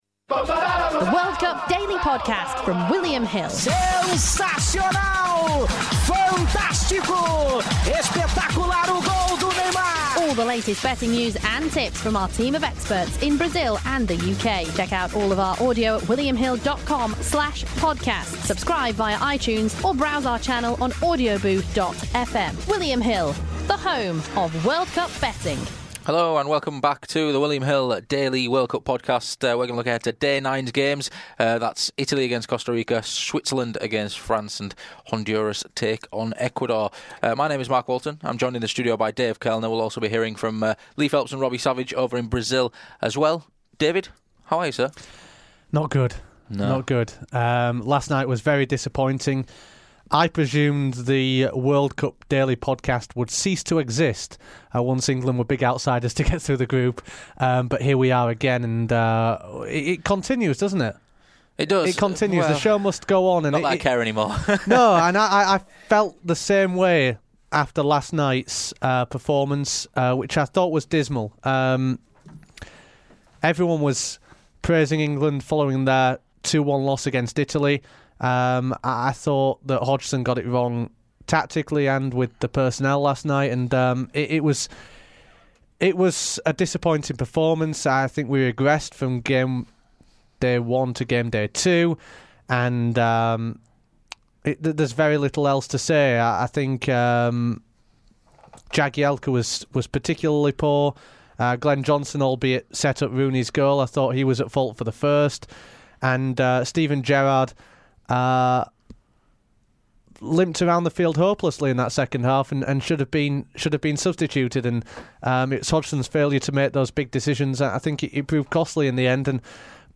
We also hear Robbie Savage's predictions in all three fixtures.